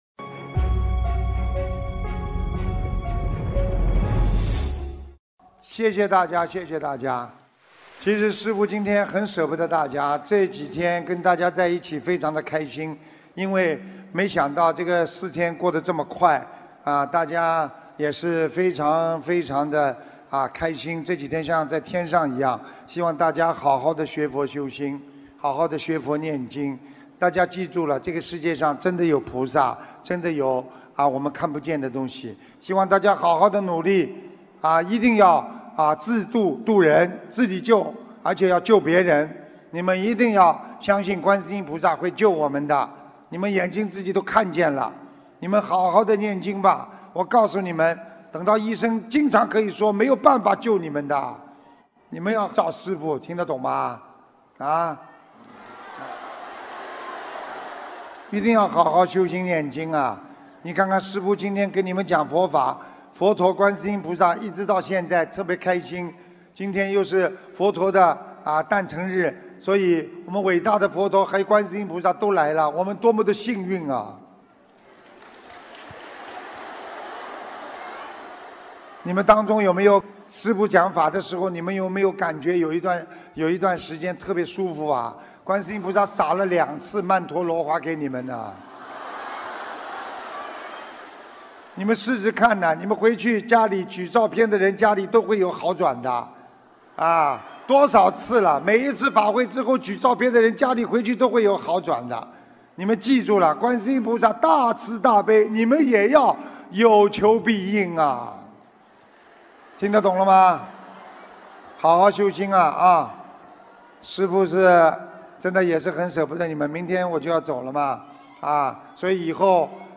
2019年5月12日新加坡世界佛友见面会结束语-经典感人开示